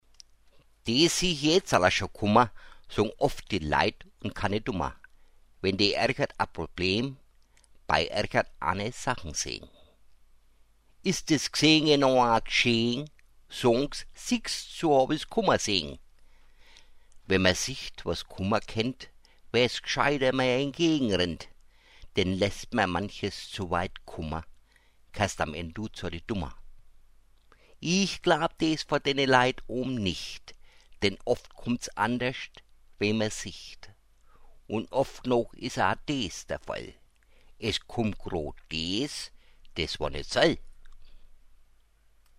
Mundart-Gedichte